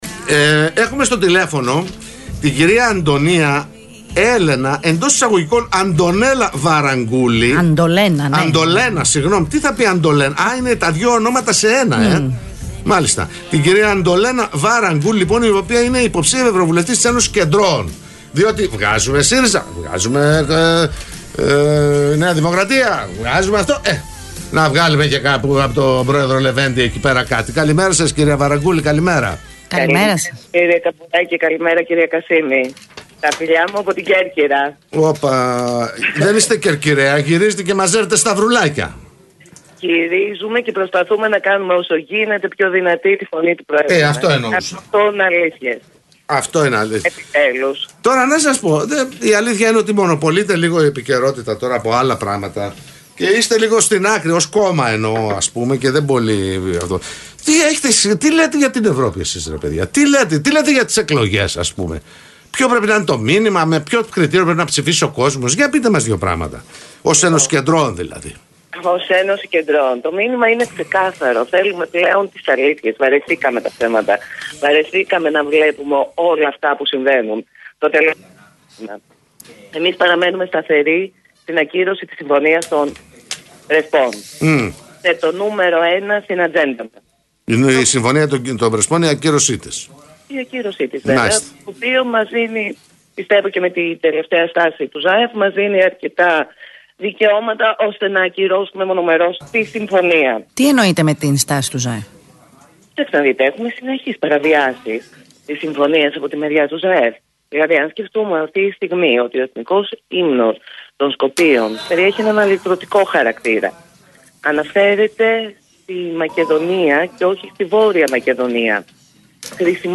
THΛΕΦΩΝΙΚΗ ΣΥΝΕΝΤΕΥΞΗ